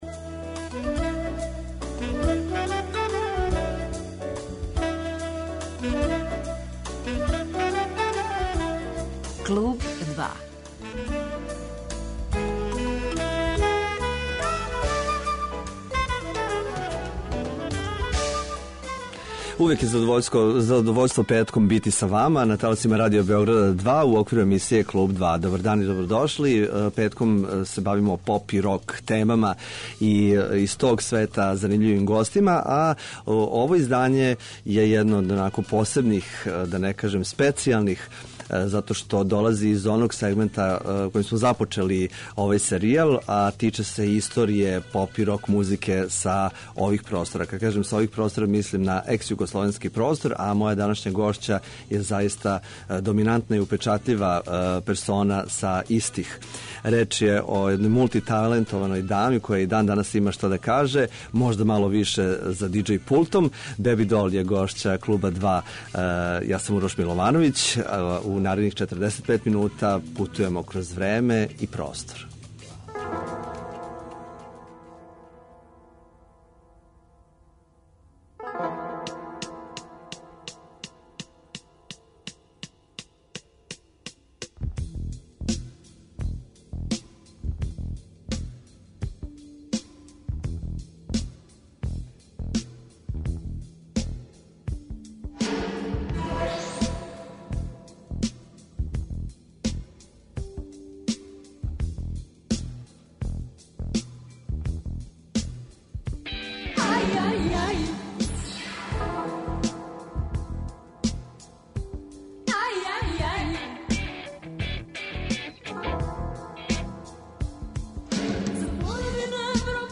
Гошћа емисије 'Клуб 2' биће Беби Дол.